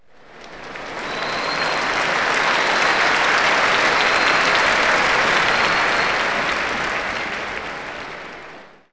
clap_051.wav